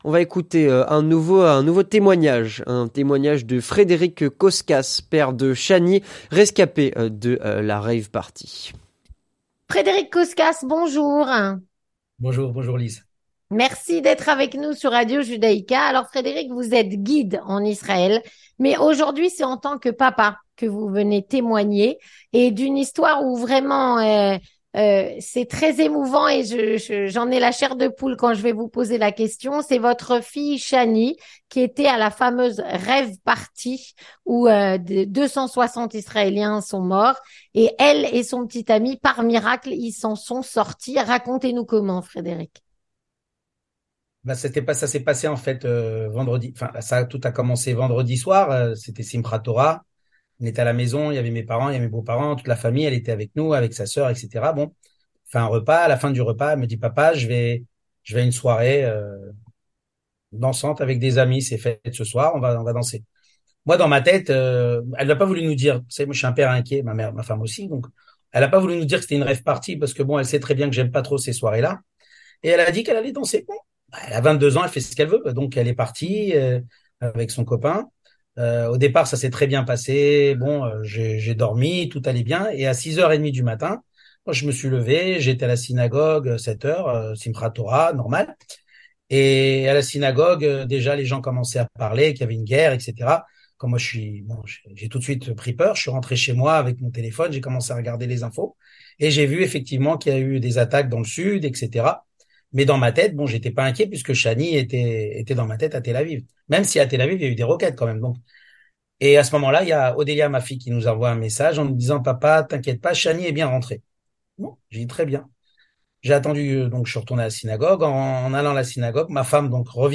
Témoignage.